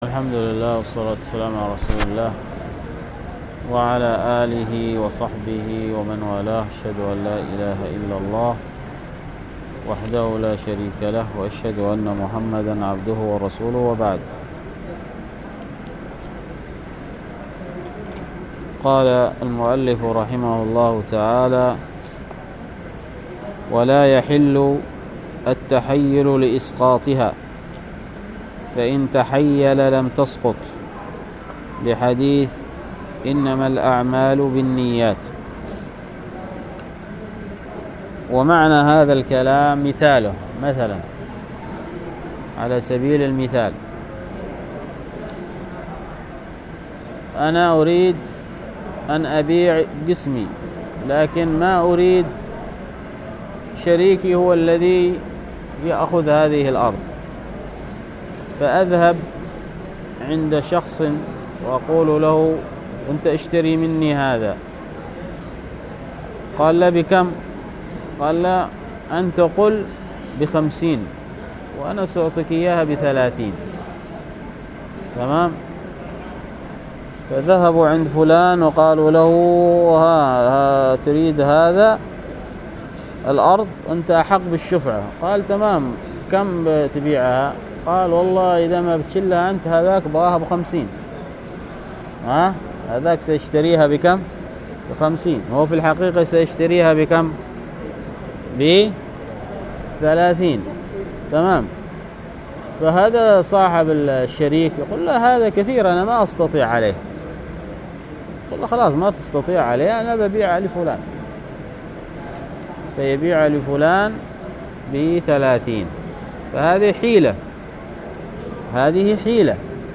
الدرس في كتاب البيوع من فتح المعين في تقريب منهج السالكين 41